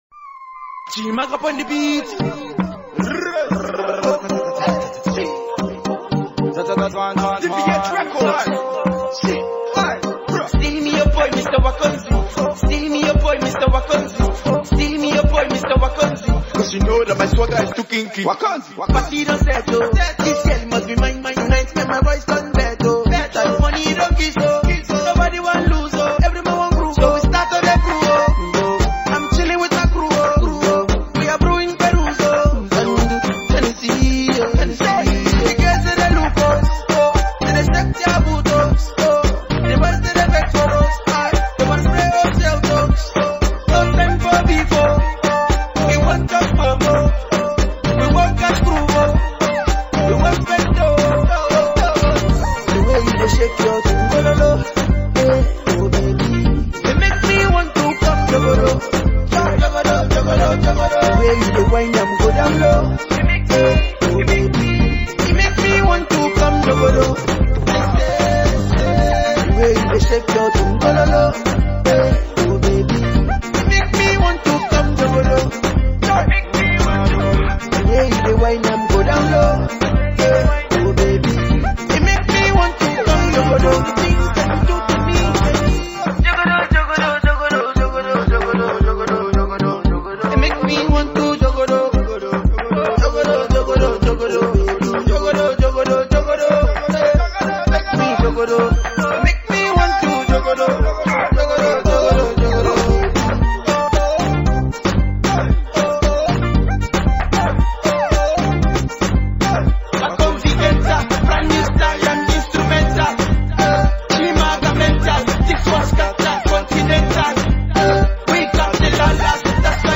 he’s back with a fresh new dance tune